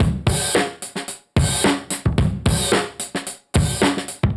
Index of /musicradar/dusty-funk-samples/Beats/110bpm/Alt Sound